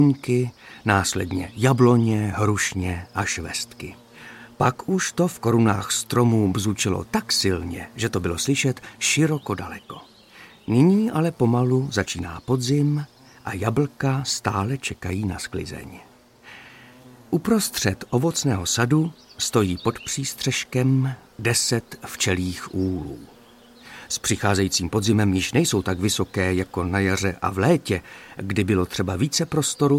Audiobook
Read: Martin Myšička